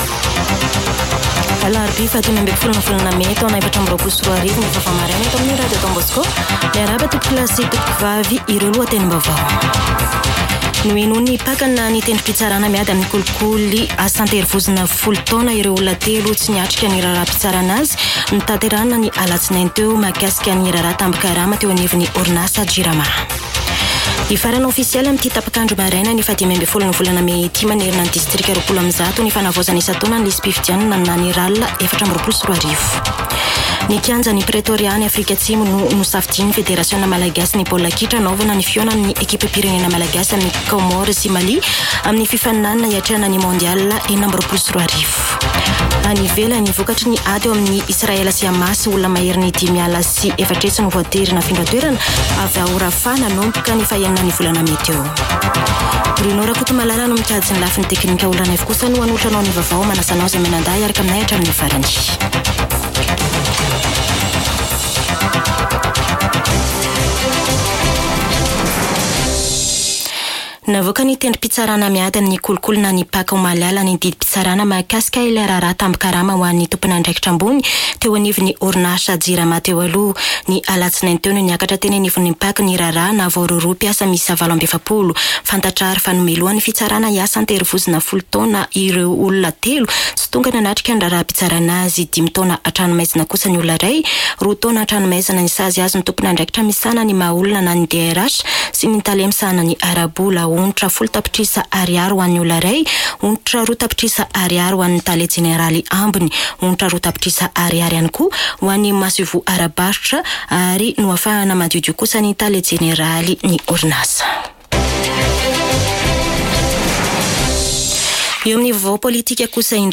[Vaovao maraina] Alarobia 15 mey 2024